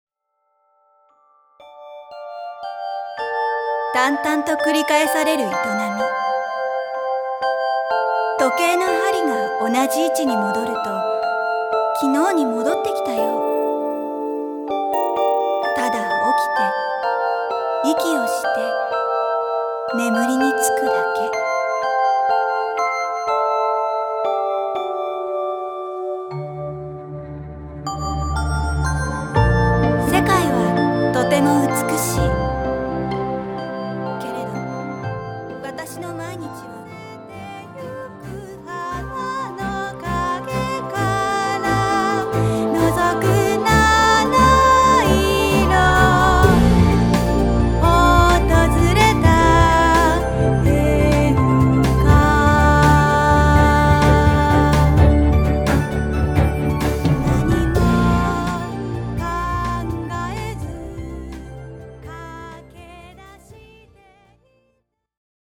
色鮮やかに描き出される絵本の世界、その世界の歯車を回す音楽、 そして、優しく語りかける歌と朗読。